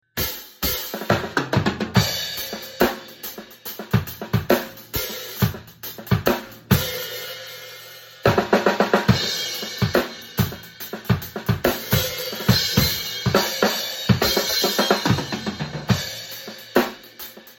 Halftime shuffle groove on drum